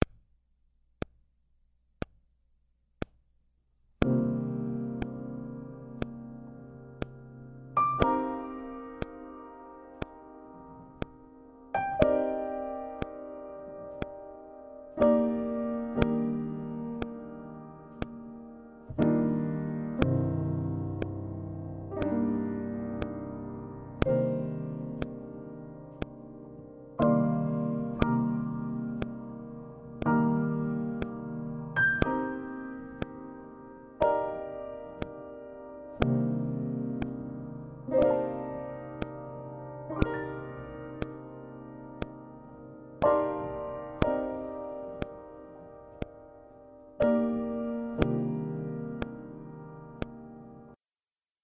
Guitare Basse et Contrebasse